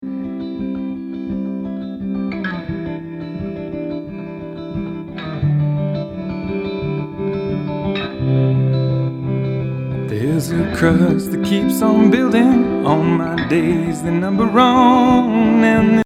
When I listened back to what was captured on the tape, I heard a distinct weird wobble type sound (I'm going to attach a small sound clip example) of what could probably be a million things, I was hoping you pros might know right off the bat.
View attachment Weird Tape Sound.mp3
What I can hear sounds like dropout, i.e. the signal fades out occasionally.